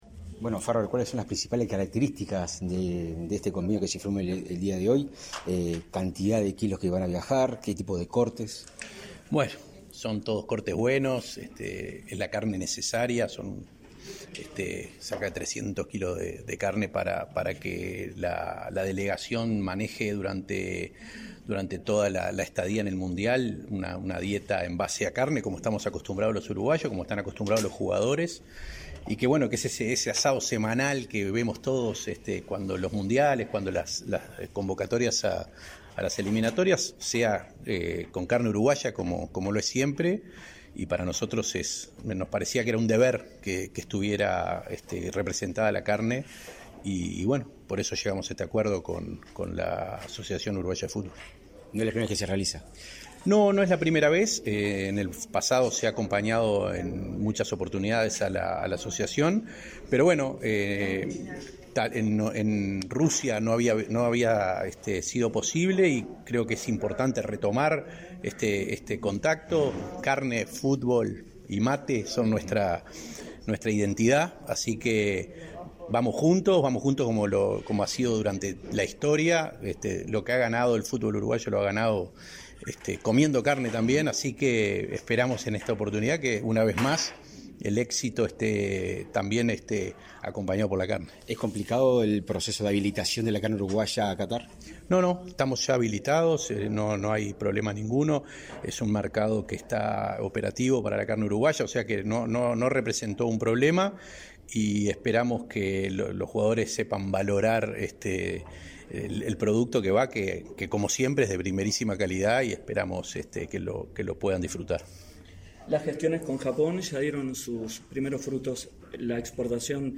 Declaraciones del presidente de INAC, Conrado Ferber
Declaraciones del presidente de INAC, Conrado Ferber 08/11/2022 Compartir Facebook X Copiar enlace WhatsApp LinkedIn Tras la firma de convenio entre el Instituto Nacional de Carnes (INAC) y la Asociación Uruguaya de Fútbol, este 8 de noviembre, para promocionar cortes nacionales en el próximo Campeonato Mundial de Fútbol, el presidente del INAC realizó declaraciones a la prensa.